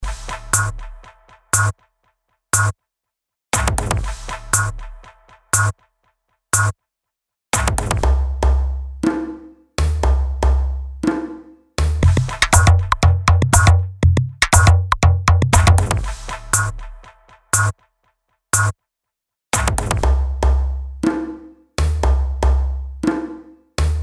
• Качество: 320, Stereo
громкие
мощные
Electronic
без слов